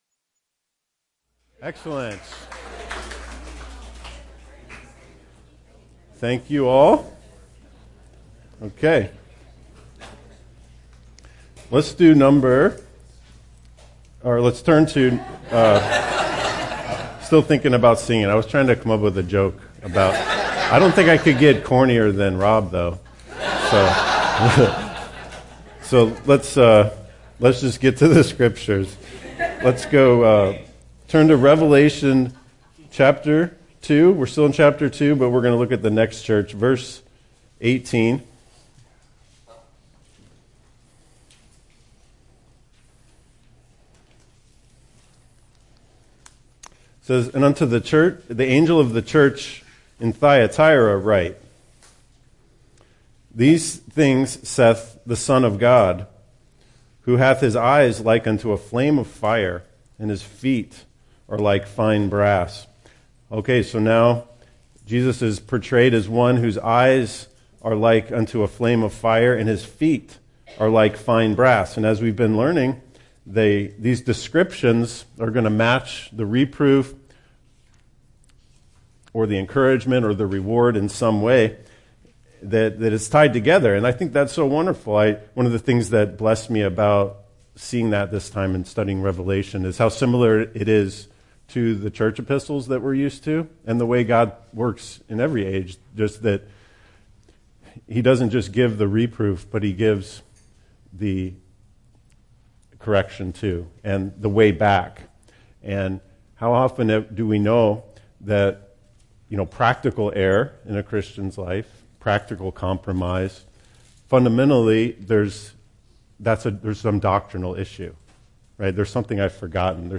Part 4 of a series of verse-by-verse teachings on the opening chapters of the book of Revelation. There is great practical learning for us in the messages from our Lord to the churches.